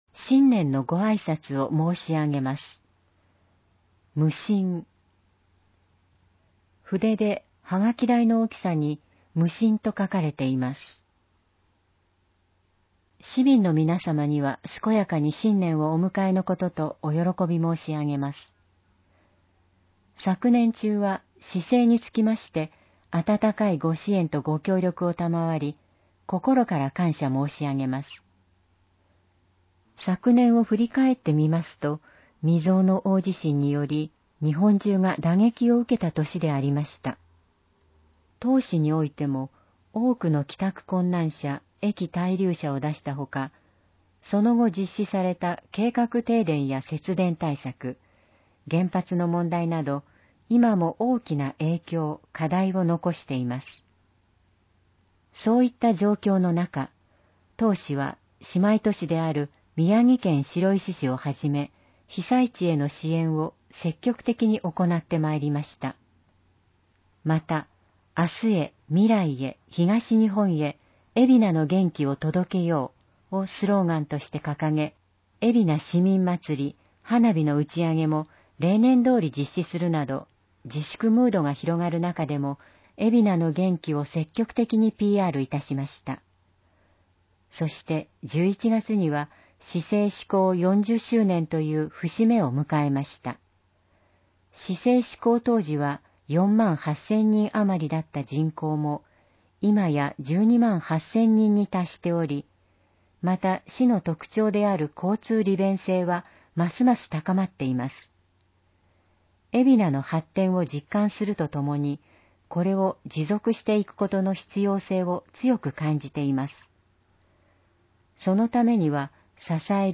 ※音声版は、音声訳ボランティア「矢ぐるまの会」の協力により、同会が視覚障がい者の方のために作成したものを、順次登載します。